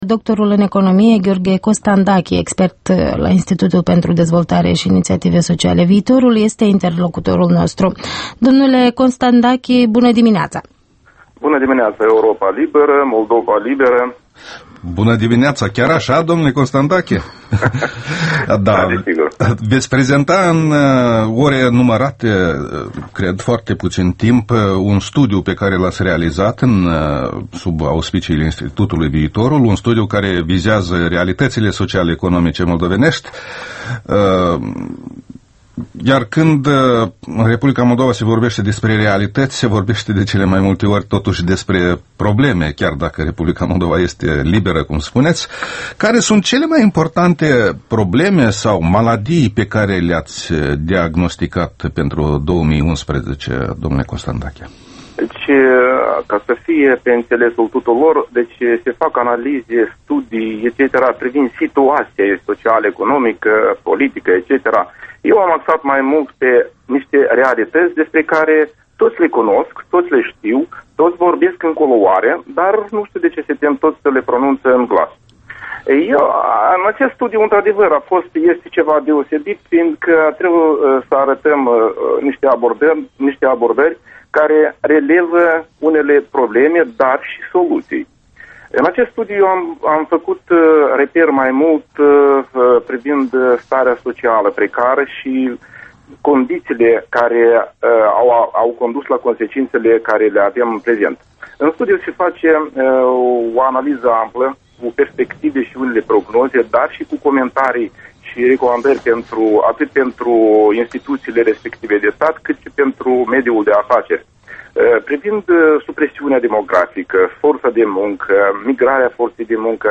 Interviul dimineții la Europa Liberă